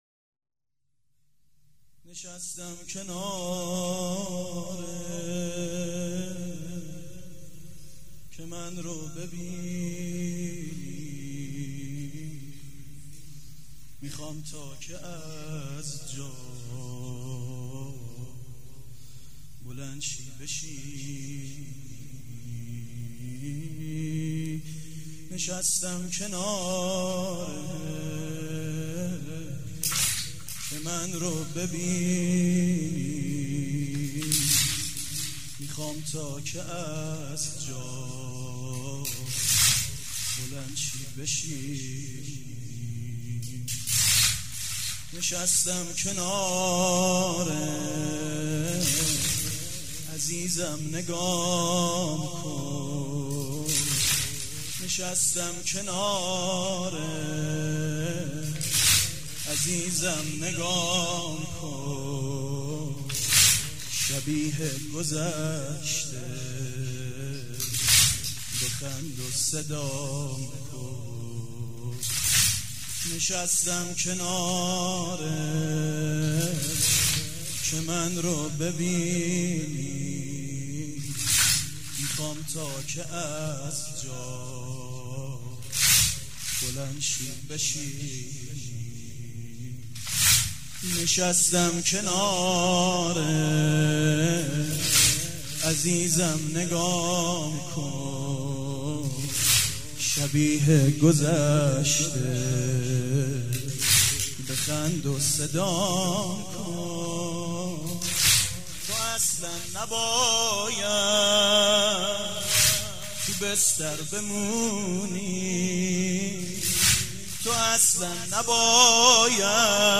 شب دوم فاطميه دوم١٣٩٤
مداح
مراسم عزاداری شب دوم